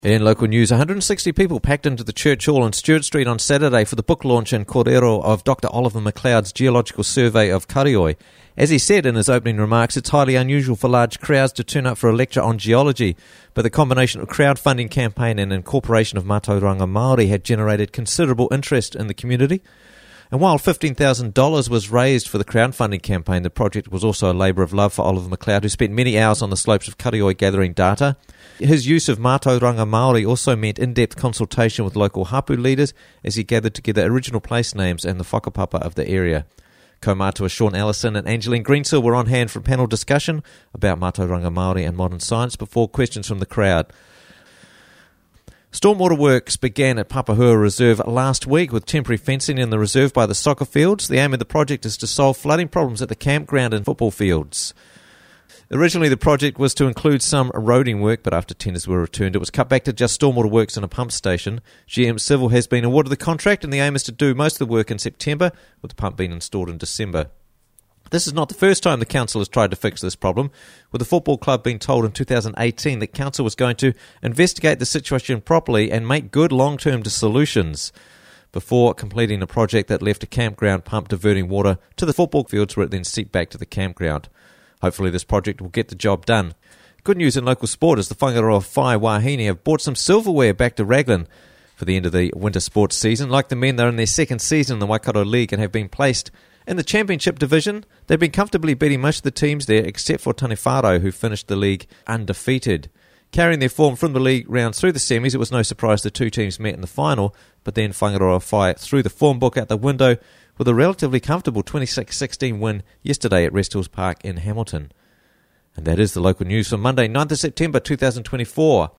Raglan News Monday 9th September 2024 - Raglan News Bulletin